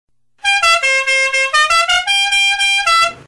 zvuk-klaksona_25022.mp3